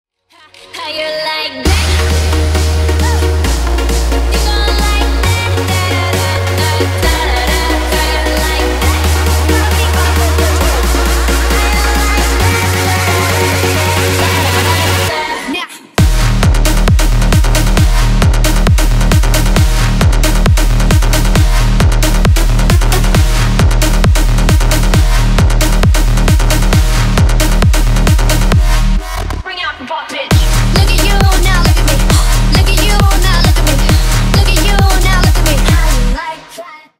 Ремикс
Танцевальные
клубные